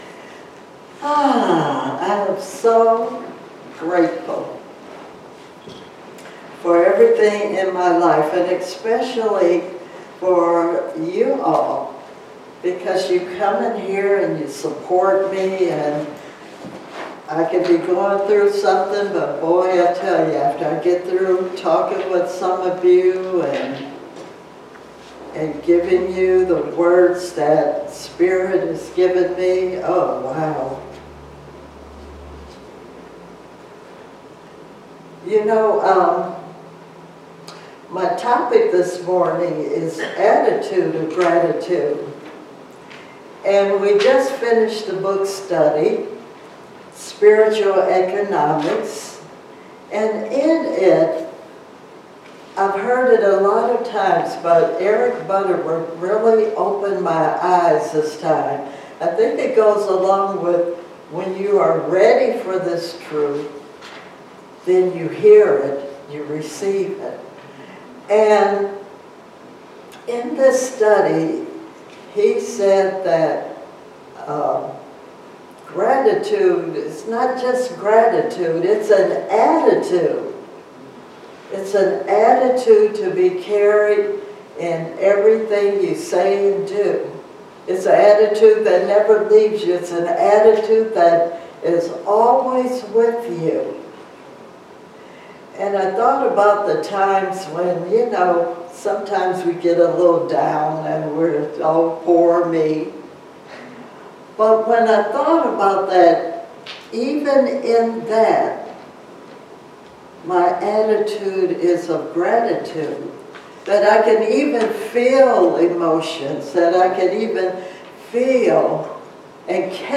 Series: Sermons 2024